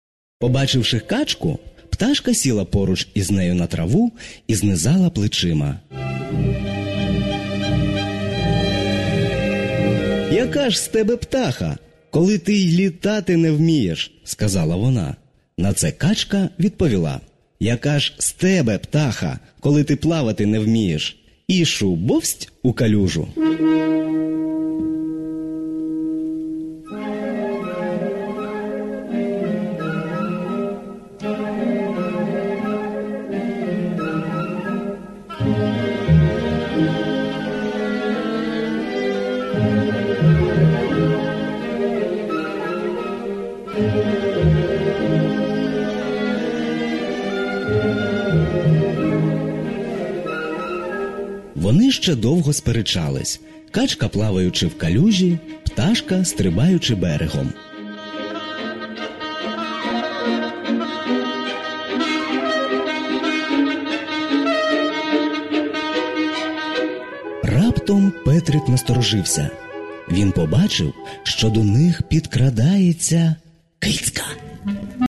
Симфонічна казка «Петрик і Вовк» (продовження)